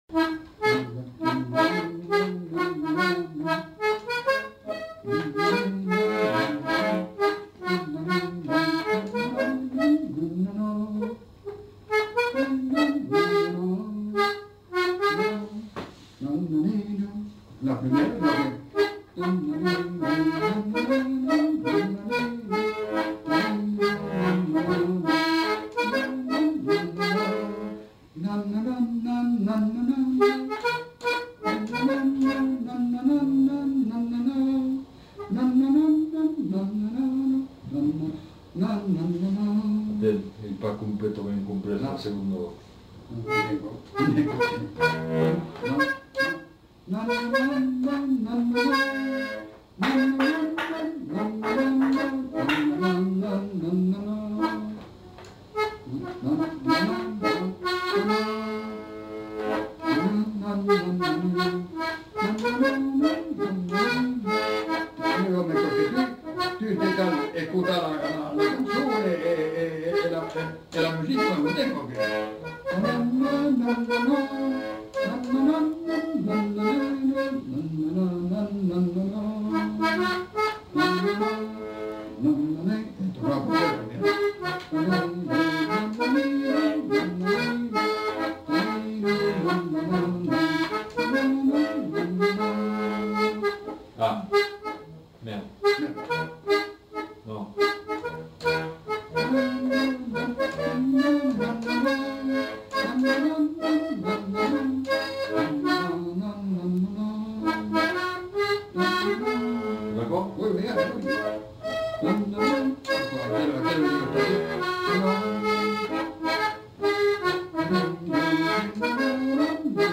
Aire culturelle : Haut-Agenais
Lieu : Villeneuve-sur-Lot
Genre : chanson-musique
Effectif : 1
Type de voix : voix d'homme
Production du son : fredonné
Instrument de musique : accordéon diatonique
Danse : mazurka